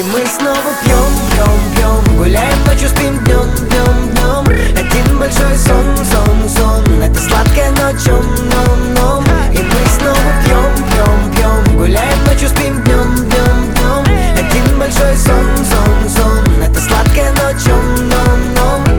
• Качество: 206, Stereo
поп
громкие
Хип-хоп
заводные